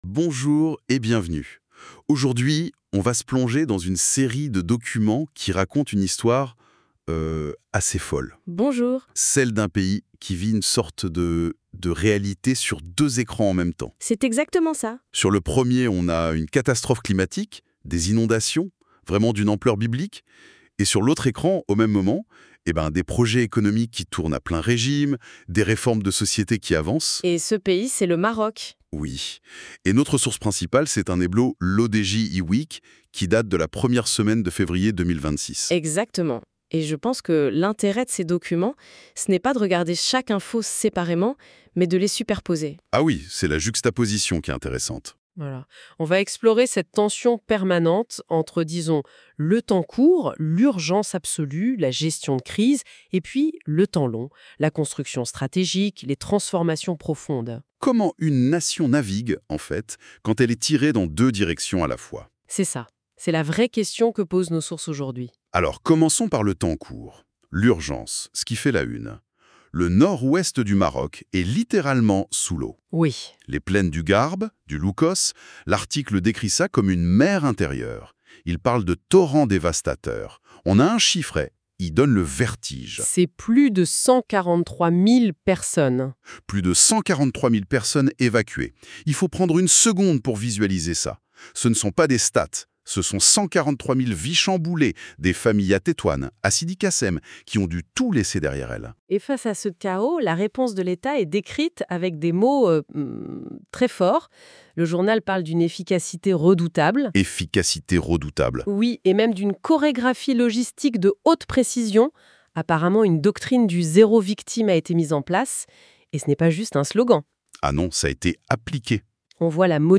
Podcast - débat IWEEK N116.mp3 (9.24 Mo)